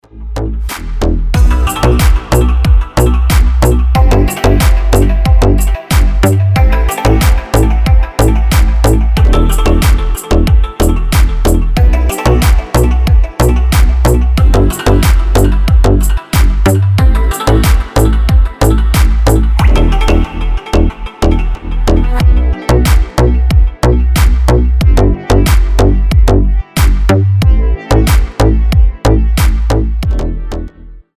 • Качество: 320, Stereo
deep house
мелодичные
без слов
медленные
восточные
качающие
G-House
Суперская восточная музыка на звонок